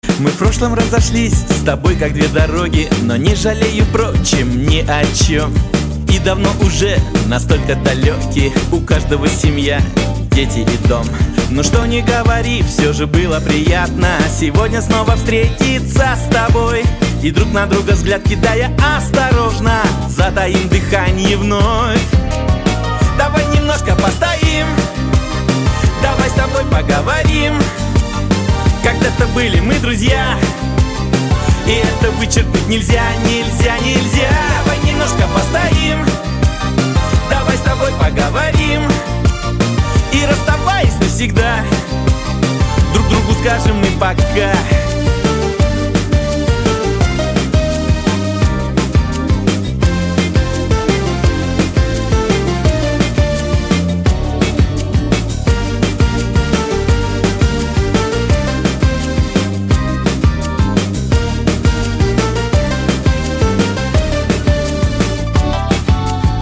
• Качество: 224, Stereo
мужской вокал
грустные
русский шансон
шансон